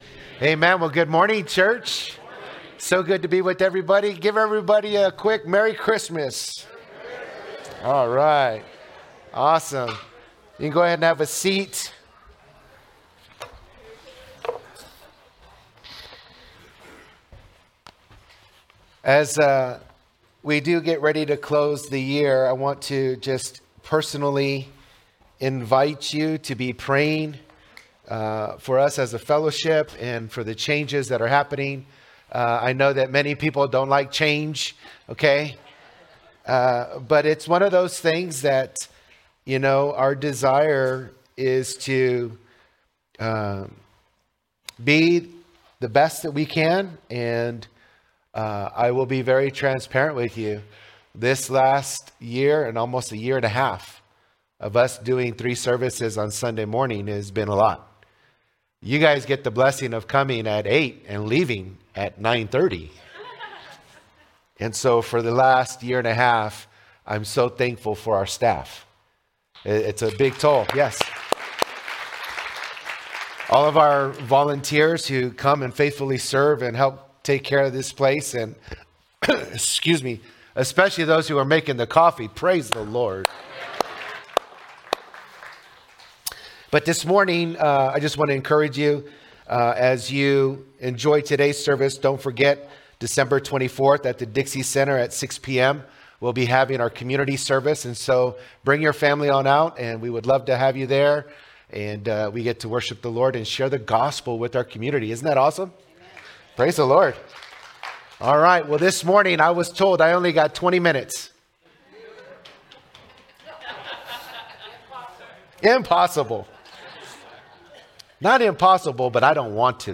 Candlelightservice2024.mp3